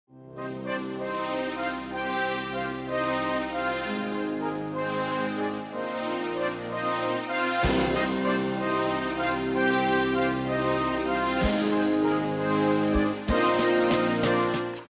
90's Korg Keyboard Sound nachstellen
Im angehängten Hörbeispiel hört man zudem, dass da mit zunehmender velocity dieser Glocken/Piano Sound reinge-layered wird. (Vermischt sich im Beispiel etwas mit Bass Drum und Overheads eines papierdünn produzierten Drumkits, aber das nur der Erklärung halber ) Aber mein Hauptfragezeichen gilt der Natur bzw Melange des Hauptsounds, könnte mir jemand erklären, was ich da höre und wie ich es am besten nachstelle?
Für mich klingt das nach Flute + oktavierten Synth-Strings.